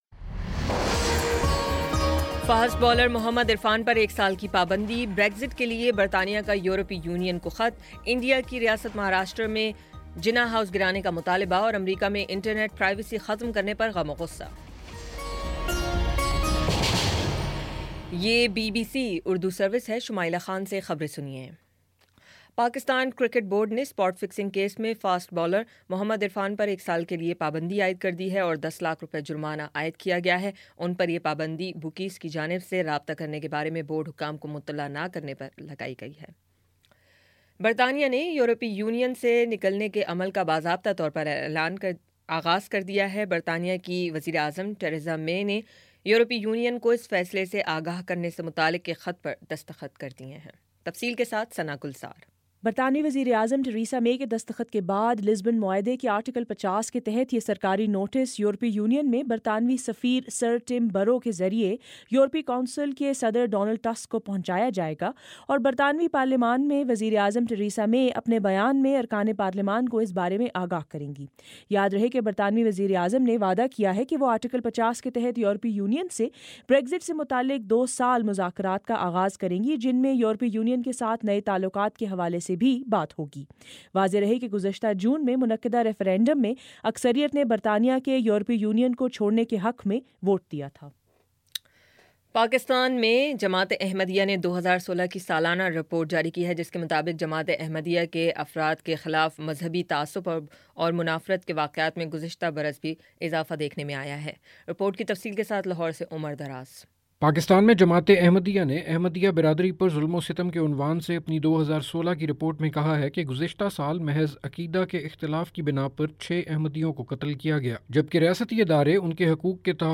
مارچ 29 : شام چھ بجے کا نیوز بُلیٹن